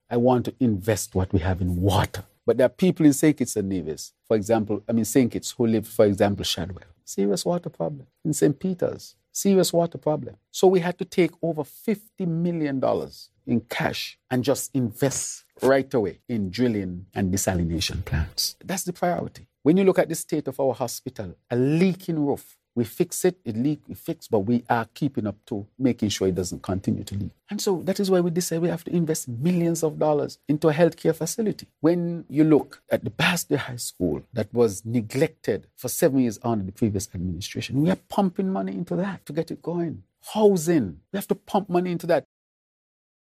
Furthermore, the Prime Minister told reporters during his Roundtable discussion on Nov. 25th, that his Government is focused on investing in sectors: